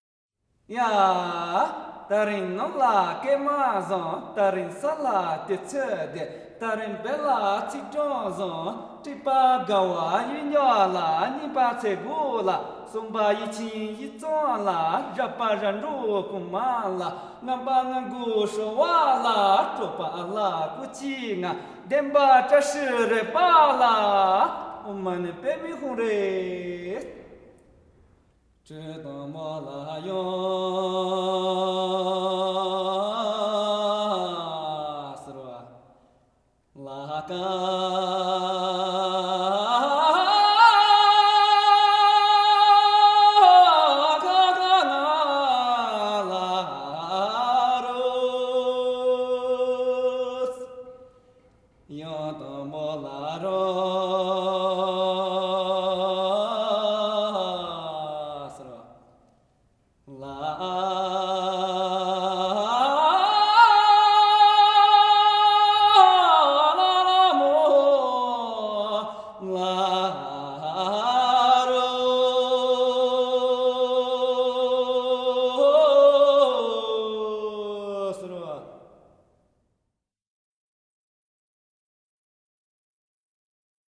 少数民族音乐系列
29首歌，旋律朴实悦耳，歌声高吭开怀，
都有嘹亮的歌声，一流的技巧。